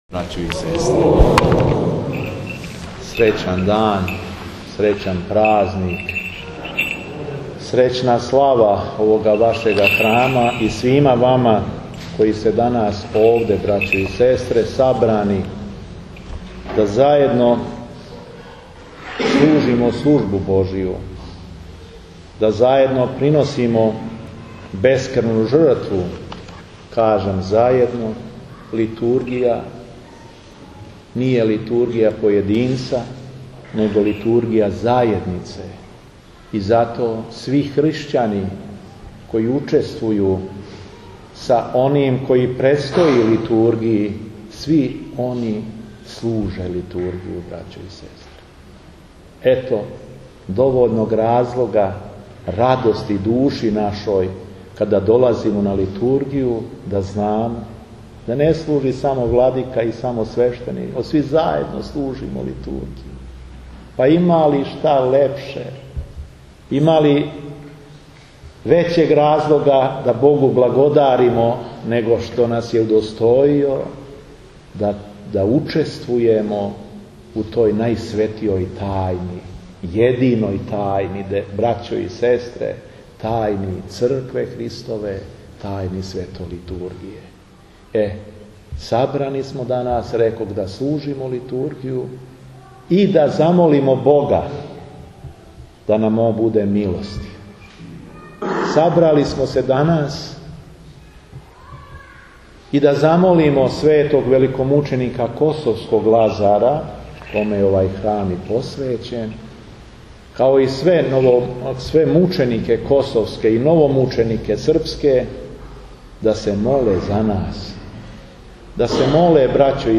Беседа епископа шумадијског Г. Јована